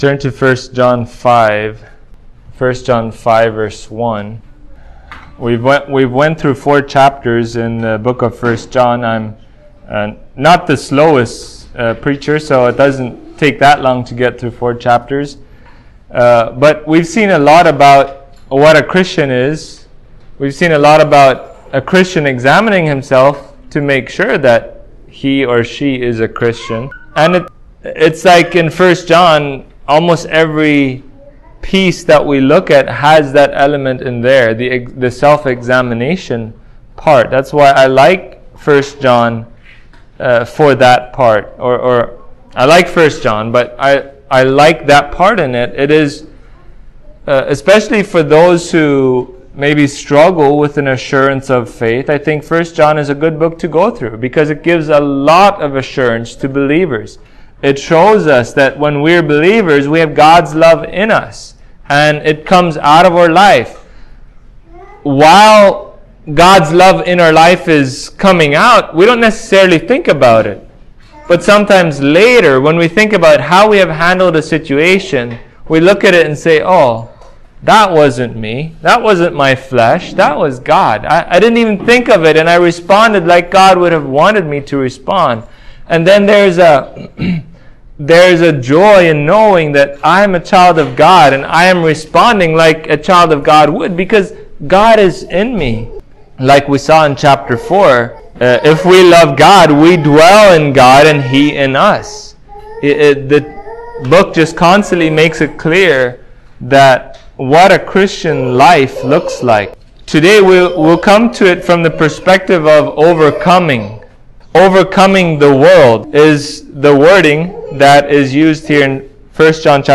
1John 5:1-5 Service Type: Sunday Morning God gives believers faith